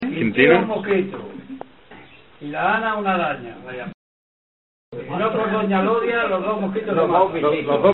Materia / geográfico / evento: Canciones populares Icono con lupa
Agrón (Granada) Icono con lupa
Secciones - Biblioteca de Voces - Cultura oral